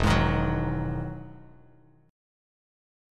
EM9 Chord
Listen to EM9 strummed